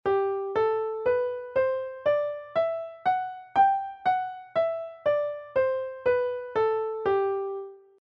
G major Scale on the Piano
G major scale.
g-major.mp3